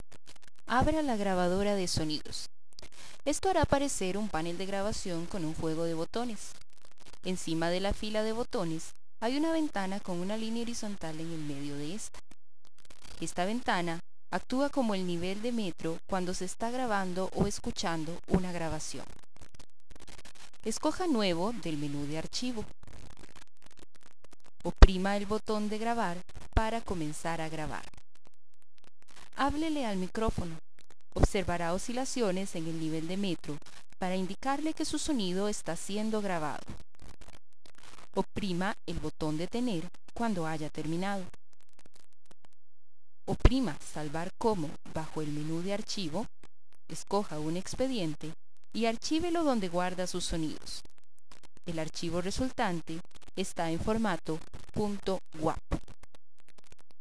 Ejemplo del uso de la grabadora de sonido.